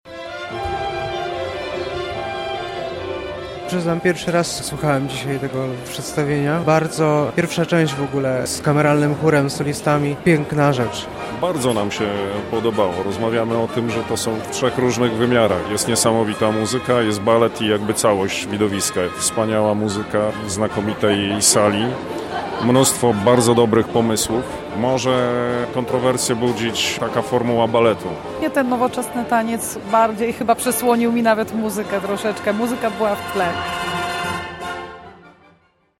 Widzowie spektaklu opowiedzieli o swoich wrażeniach.